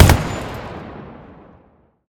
gun-turret-shot-2.ogg